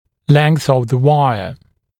[leŋθ əv ðə ‘waɪə][лэнс ов зэ ‘уайэ]длина проволоки